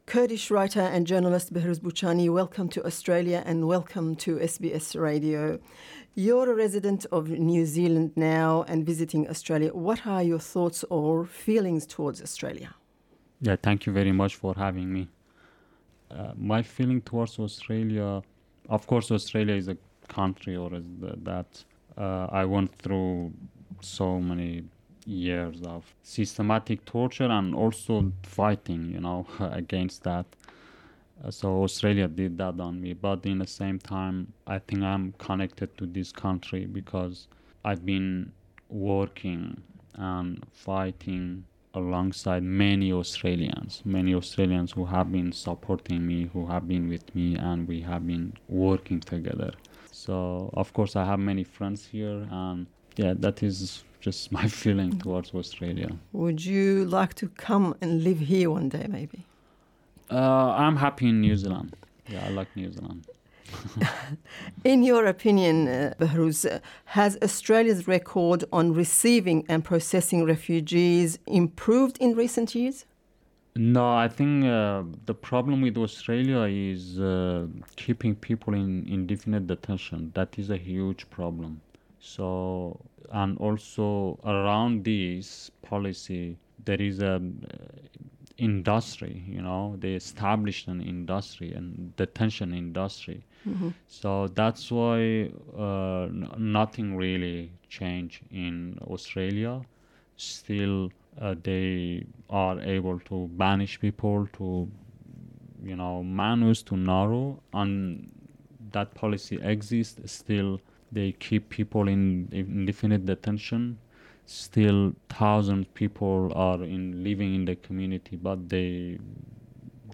Writer and refugee advocate Behrooz Boochani.